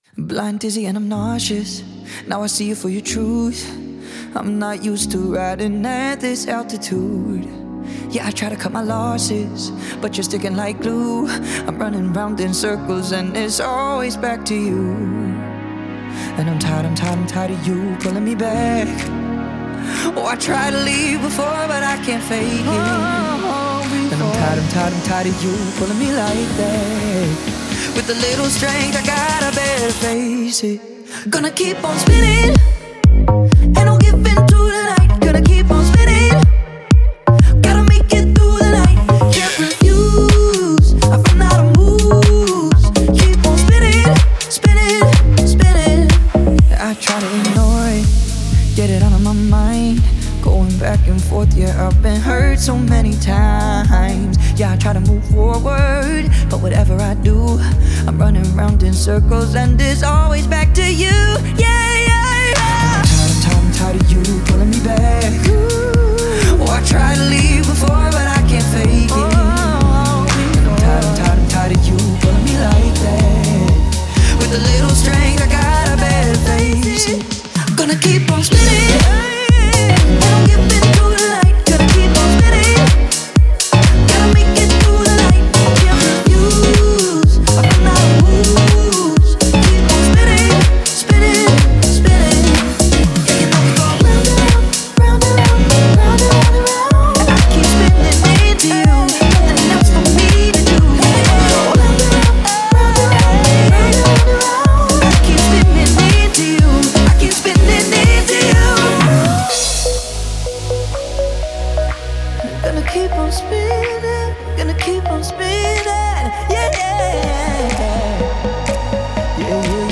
это яркая и динамичная песня в жанре поп и EDM